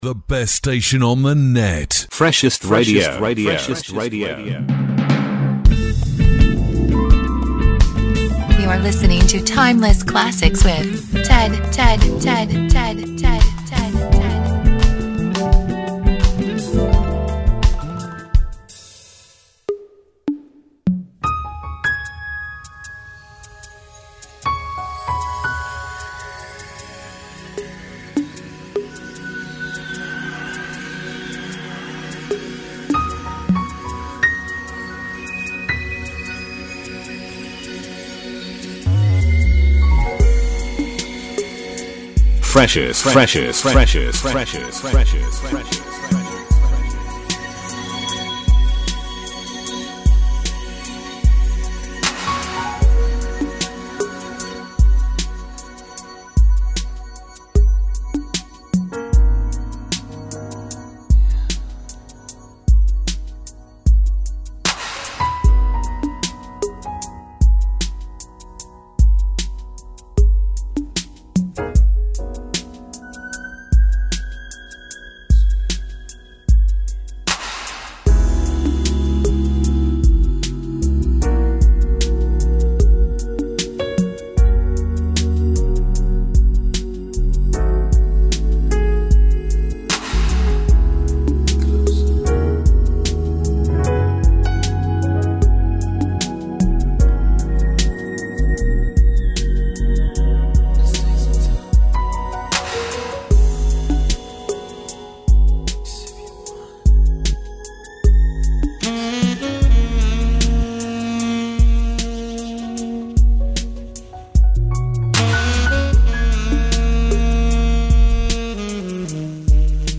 A show for classic R&B music lovers.